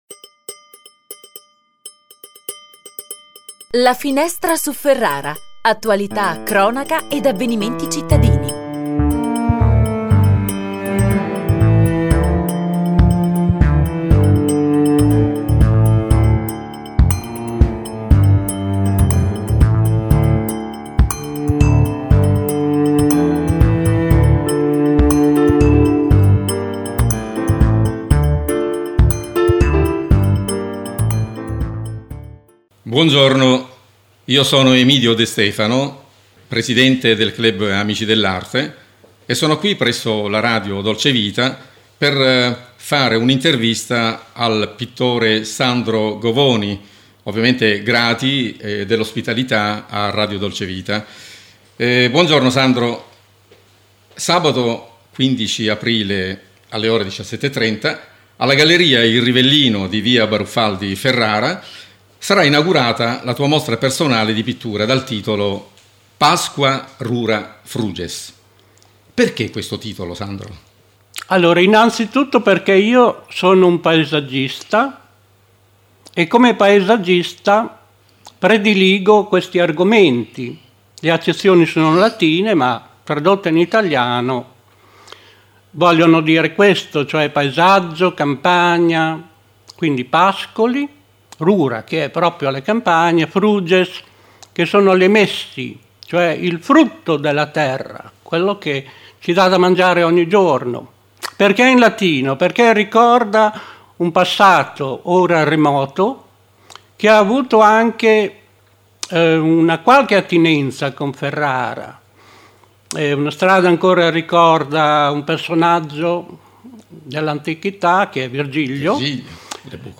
Ecco l’Intervista mandata in onda su Radio Dolcevita: